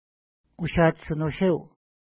Ushatshi-tshinusheu Next name Previous name Image Not Available ID: 526 Longitude: -60.3717 Latitude: 53.4203 Pronunciation: uʃa:tʃ-tʃinuʃew Translation: Place For Pike Official Name: Gosling Lake Feature: lake